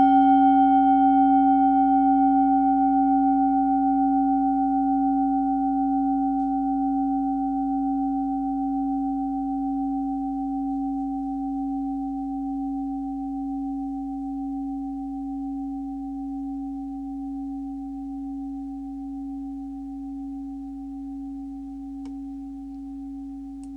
Klangschalen-Typ: Bengalen
Klangschale Nr.5
Gewicht = 660g
Durchmesser = 14,5cm
(Aufgenommen mit dem Filzklöppel/Gummischlegel)
klangschale-set-1-5.wav